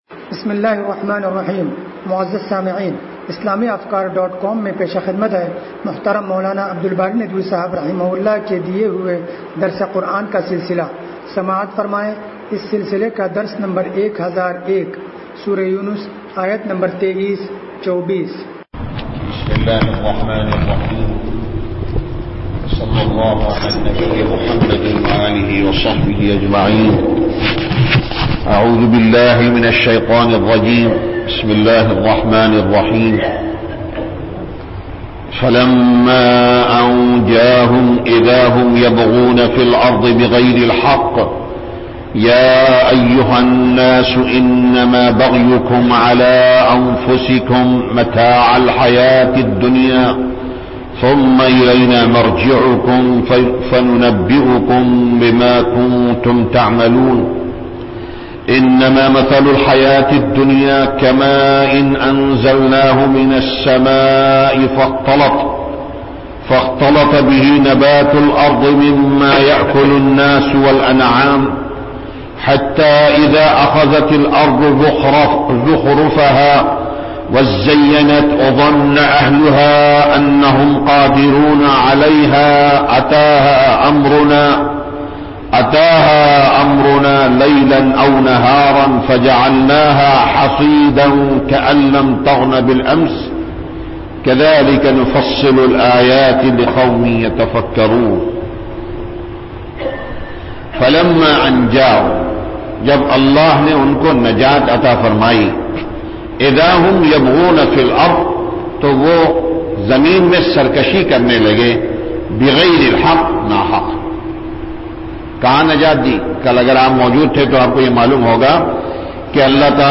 درس قرآن نمبر 1001